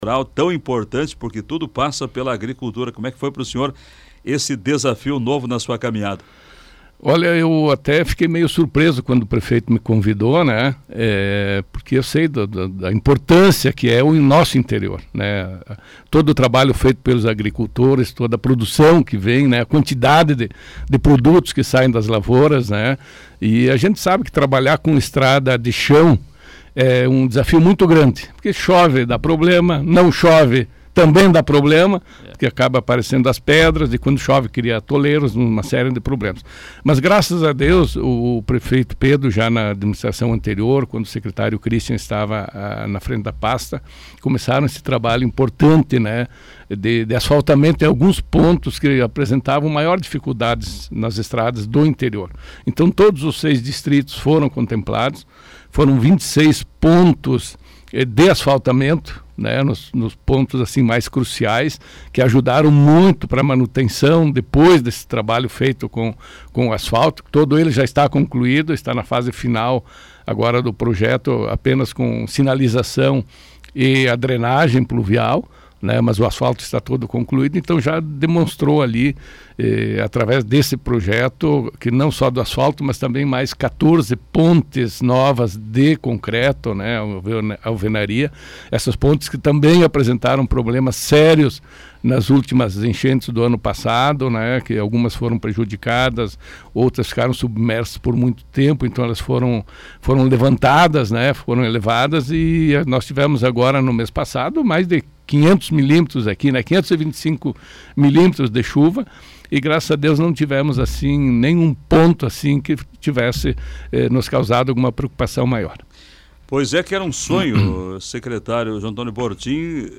O secretário municipal de Agricultura e Desenvolvimento Rural de Passo Fundo foi entrevistado nesta quarta-feira, 16, no programa Na Ordem do Dia, da Rádio Planalto News (92.1).